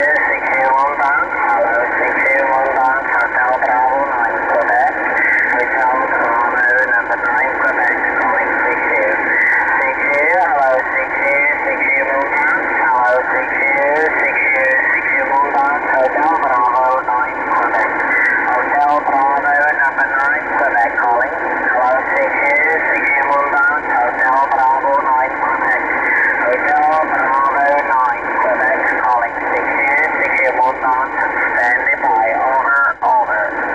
Tym razem było słychać kilka stacji wyjątkowo mocno.
- w załączeniu nagranie jak było u mnie słychać jego "CQ MOON".